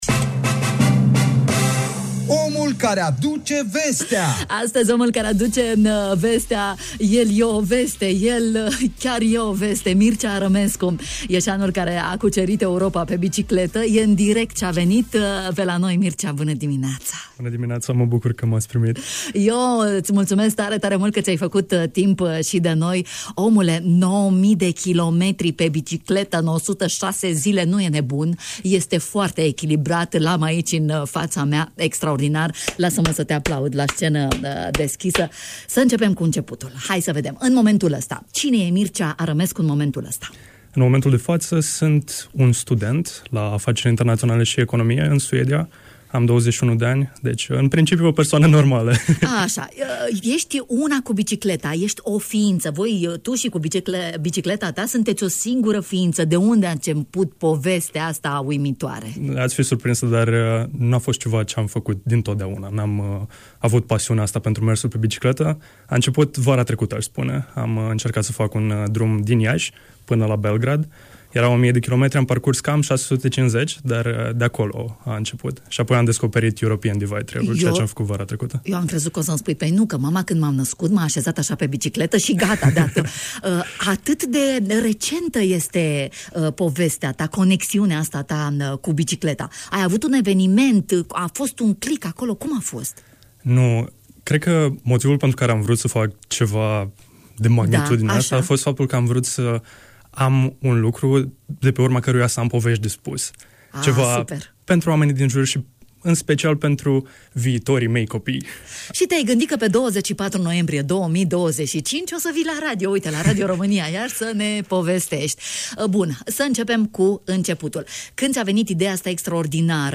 în direct la Radio România Iași